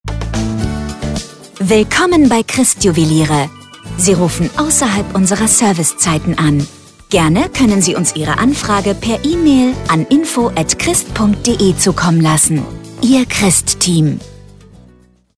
Telefonansage Christ Juweliere